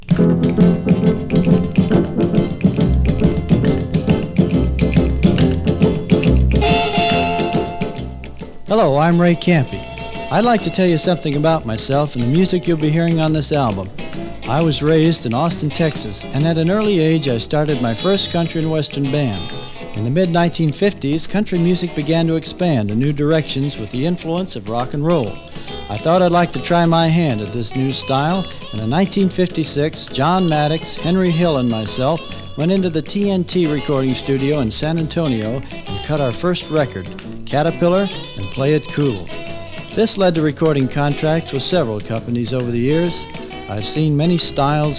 Rock'N'Roll, Rockabilly　UK　12inchレコード　33rpm　Mono